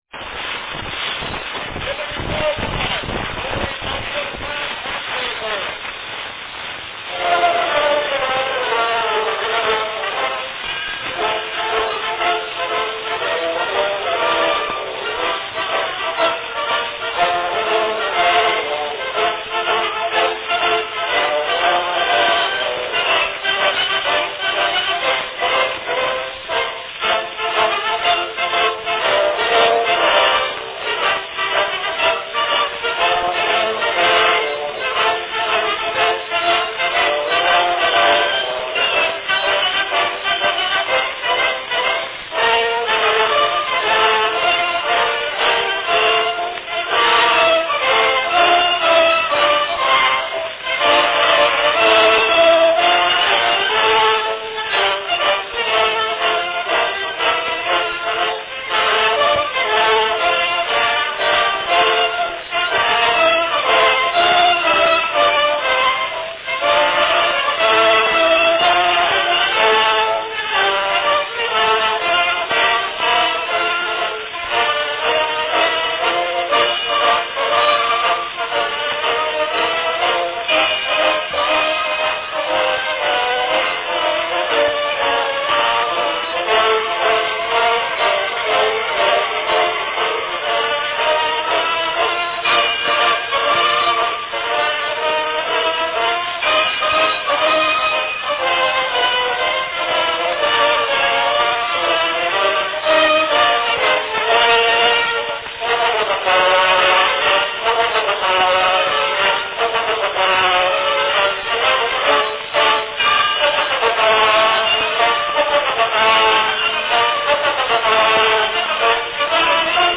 Category Band
Performed by Edison Concert Band
Announcement "Liberty Bell March, played by the Edison Concert Band."
The announcement portion of this cylinder is damaged, but can still be made out.
Edison's early cylinders were recorded at 125 RPM and would run for about 2 & 1/2 minutes.